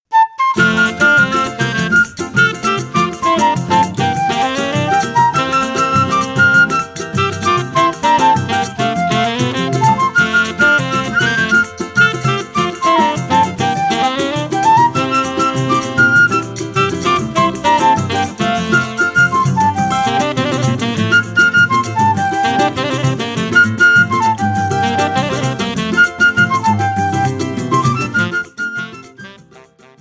– áudio completo com regional, solo e contraponto.
flauta
saxofone tenor